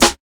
Snare (7).wav